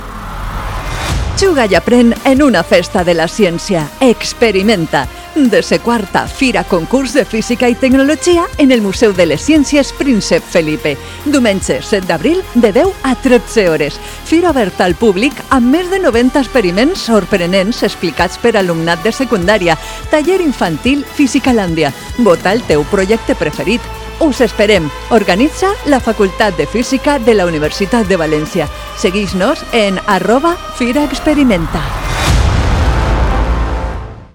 Cuña de radio y spot sobre Experimenta 19